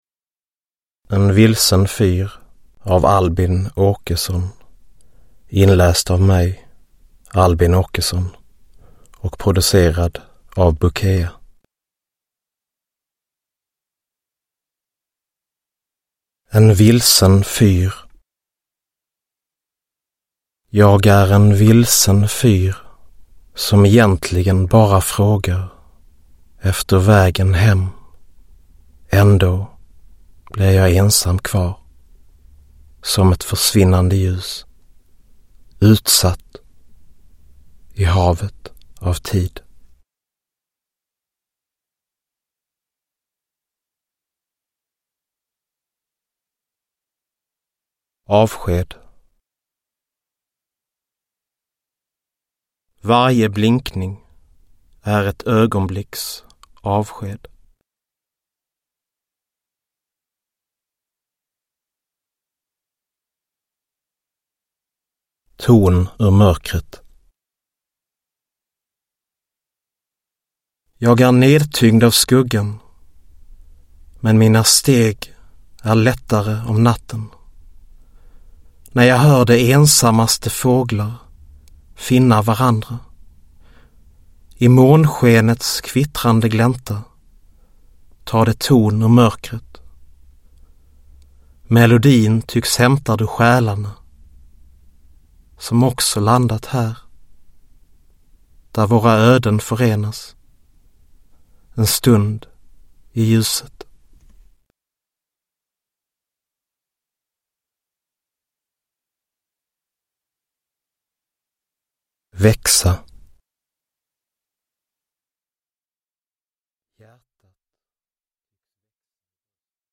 En vilsen fyr – Ljudbok